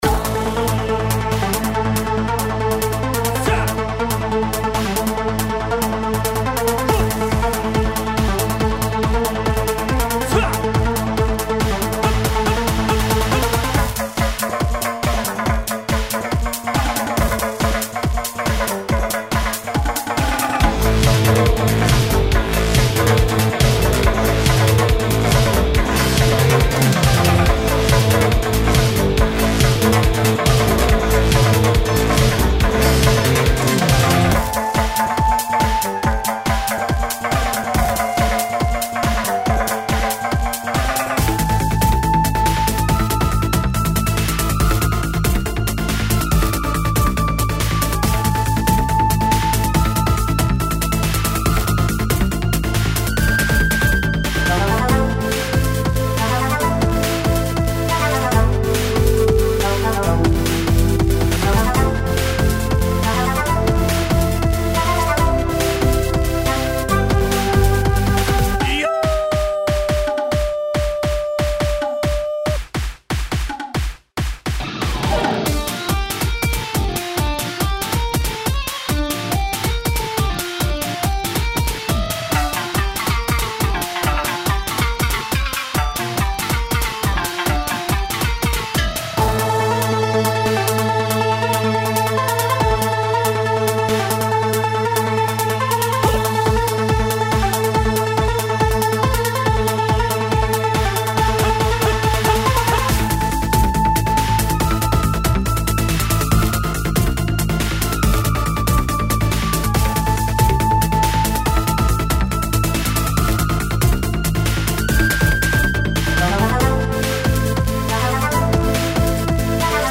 切なく、どこか懐かしさを感じさせる和風戦闘BGM
ループ仕様なので、プレイ中も自然に流れ続けます。
• BPM：140（緩やかな中にリズムの揺らぎを含む）
• ミックス面では空間系エフェクトを控えめにし、より“現場の臨場感”を重視しました。